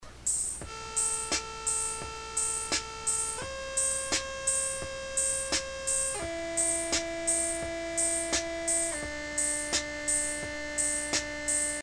thirdsongsecondkeyboardpart.mp3